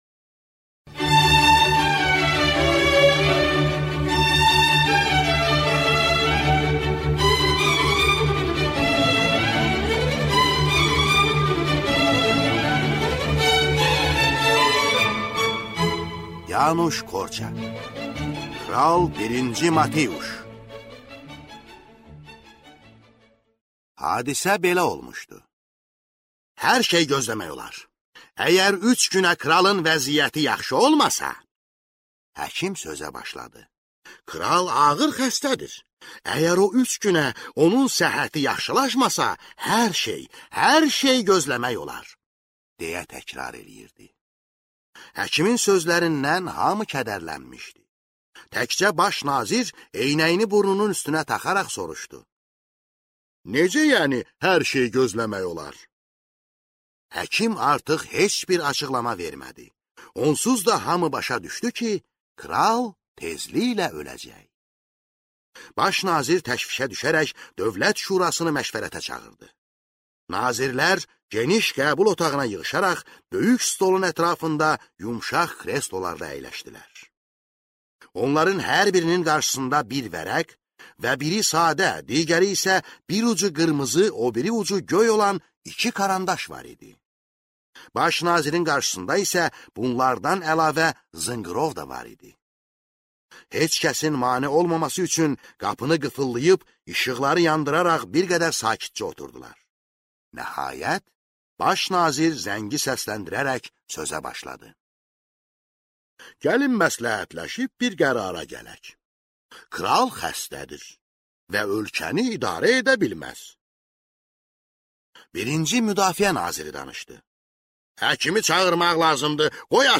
Аудиокнига Kral Birinci Matiuş | Библиотека аудиокниг